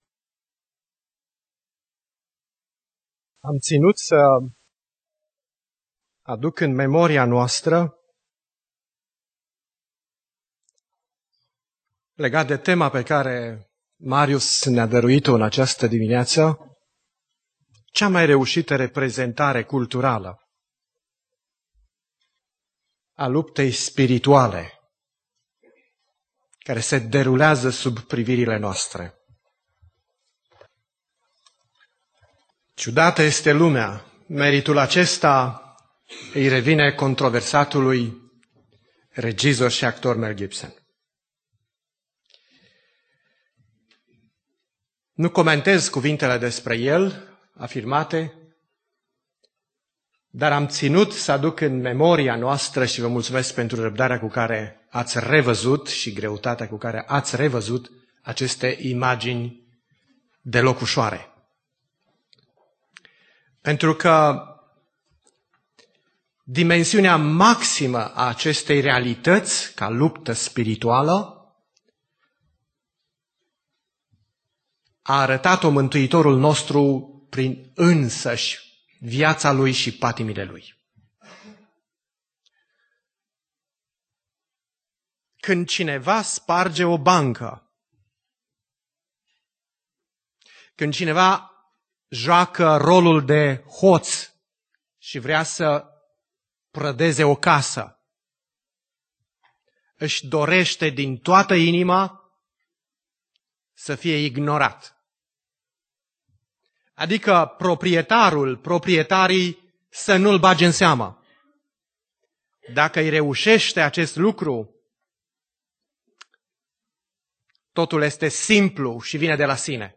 Predica Aplicatie - Ev. dupa Ioan cap 16